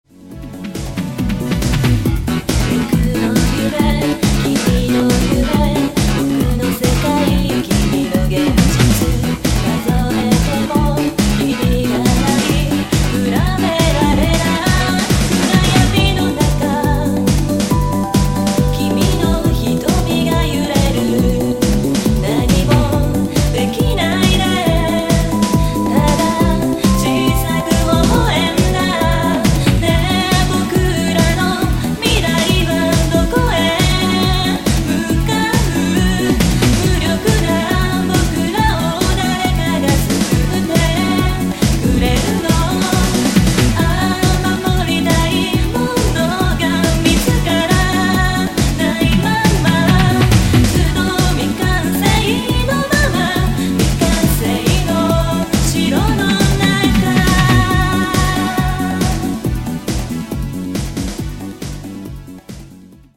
使用されるVocal曲、BGMから数点を選びカバーしたものと